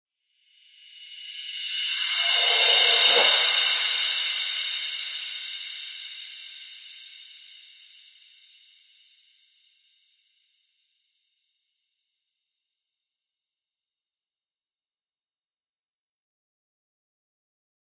反转镲片 " Rev Cymb 17 混响器
Tag: 回声 金属 FX 反向